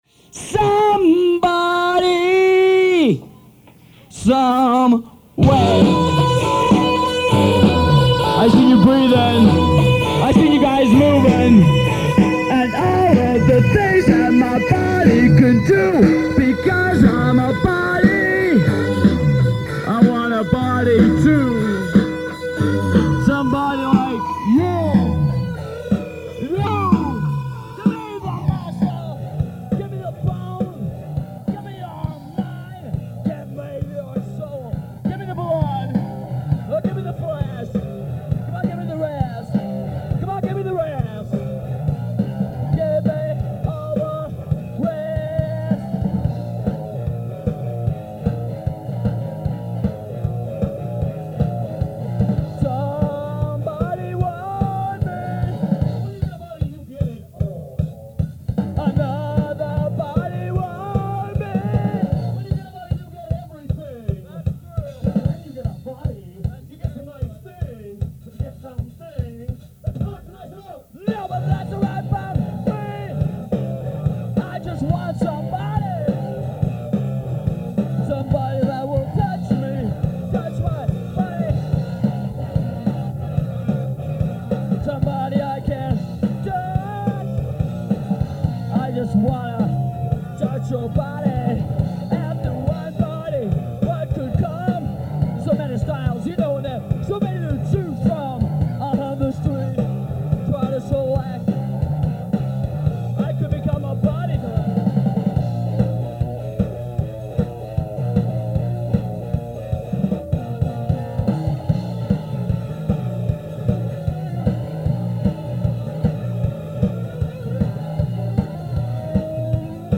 Days Inn – Rapid City, SD